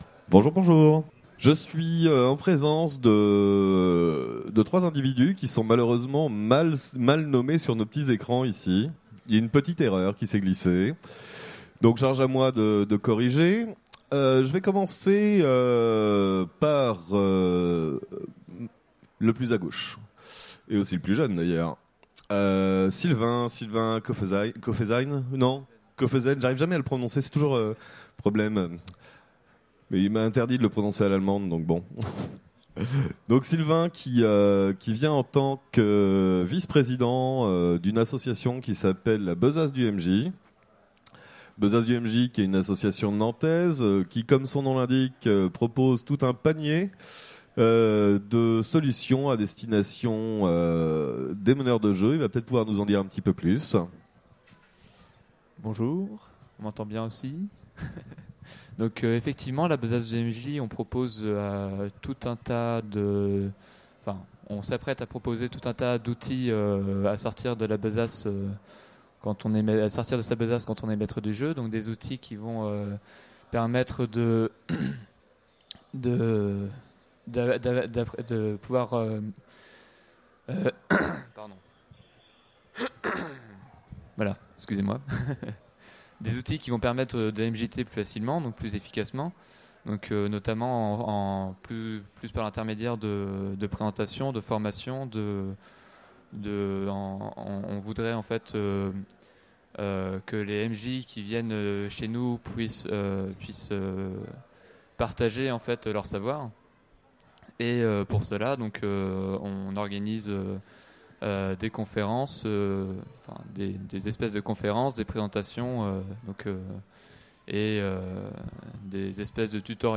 Utopiales 2016 : Conférence Les machines au service du jeu de rôle